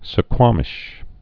(sə-kwämĭsh)